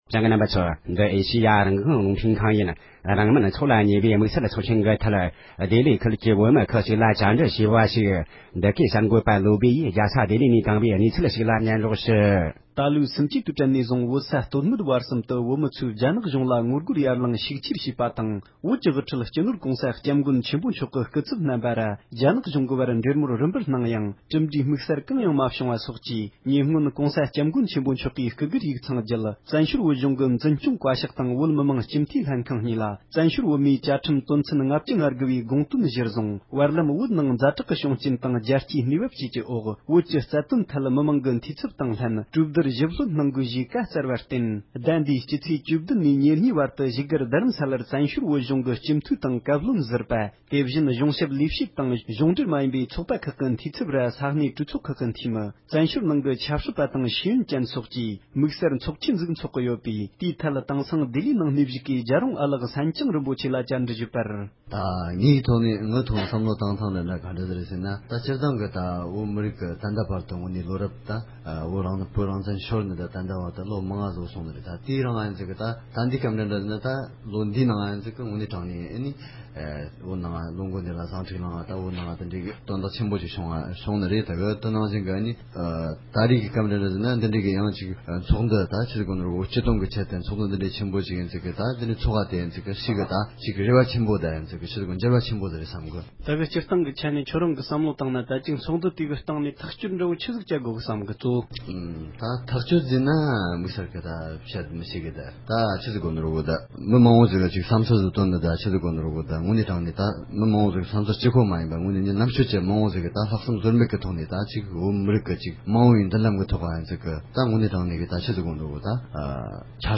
ཚོགས་ལ་ཉེ་བའི་དམིགས་བསལ་ཚོགས་ཆེན་ཐད་འདི་གའི་གསར་འགོད་པས་ལྡི་ལི་བོད་མི་ཁག་ལ་བཀའ་འདྲི་ཞུས་པ།